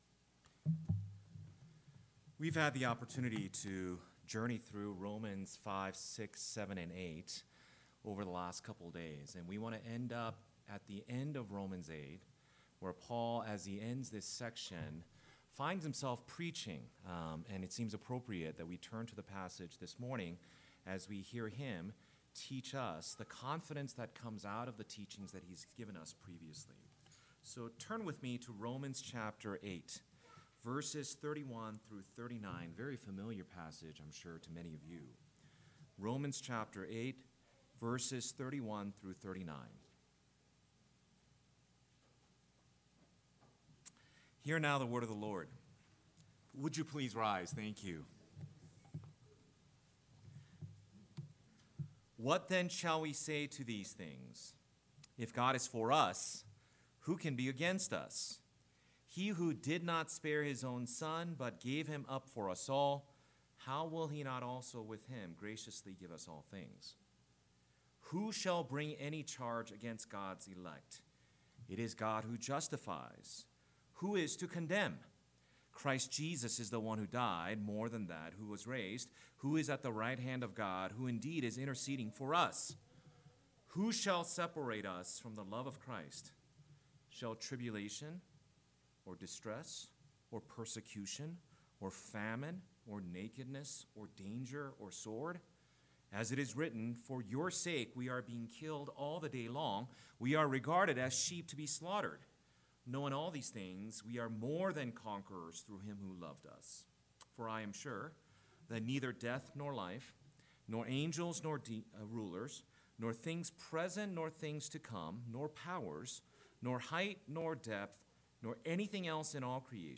2021 Cheyenne Reformation Conference Session 5 – Sunday AM – Northwoods Sermons